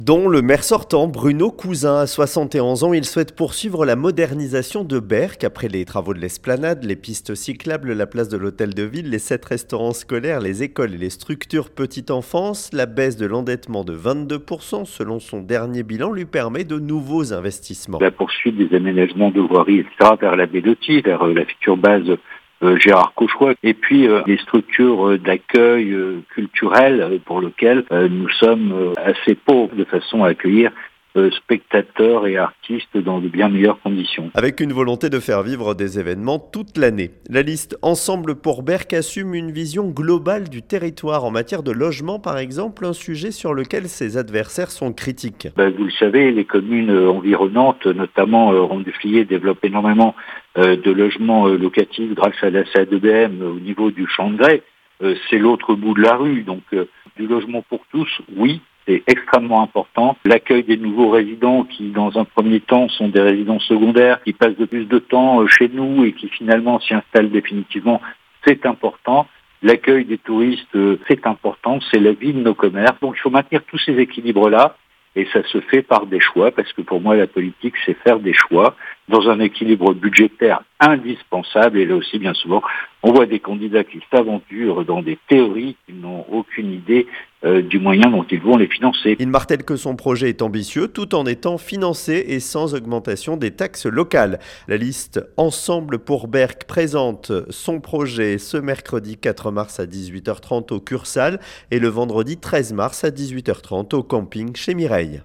Après un premier cycle de grands chantiers, il entend poursuivre la transformation de la station balnéaire, tout en promettant rigueur budgétaire et stabilité fiscale. Entretien.